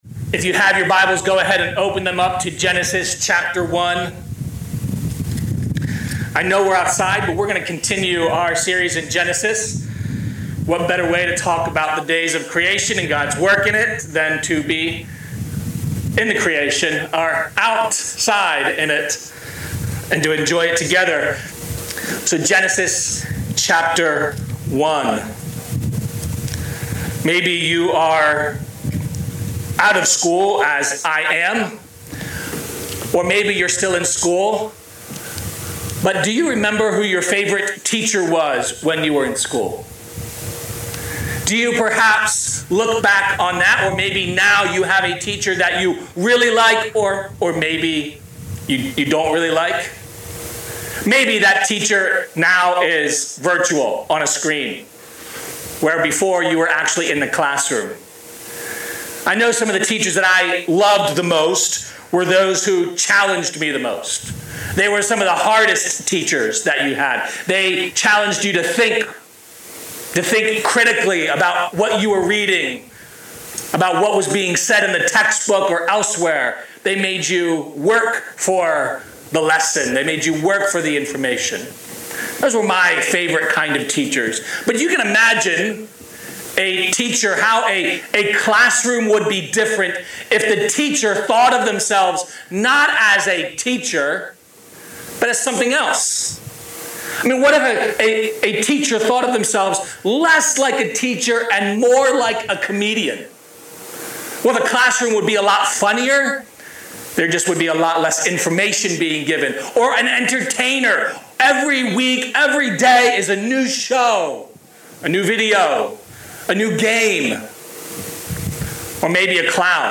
Outdoor Service Genesis 1:26-31